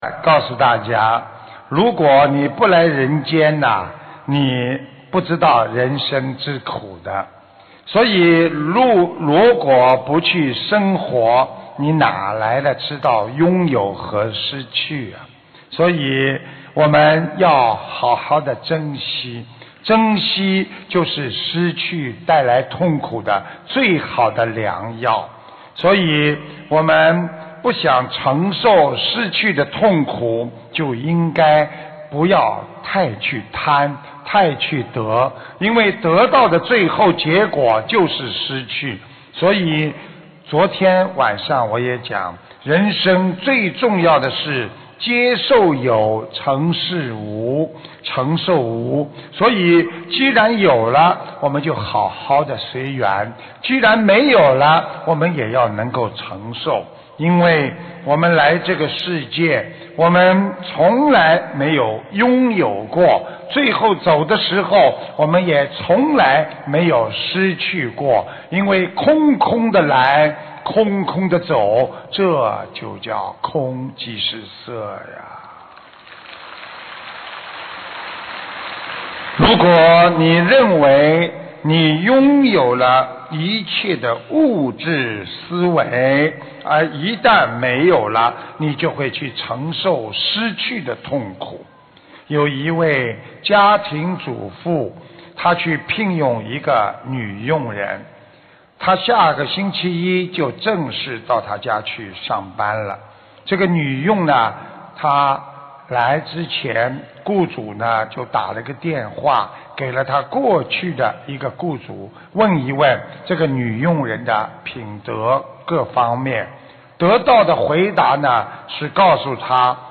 目录：☞ 2016年8月_马来西亚_槟城_开示集锦